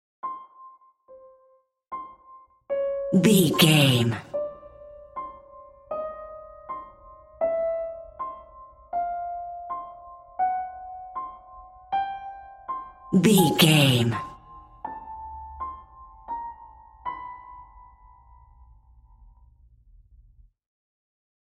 In-crescendo
Thriller
Aeolian/Minor
scary
ominous
dark
haunting
eerie
stinger
short music instrumental
horror scene change music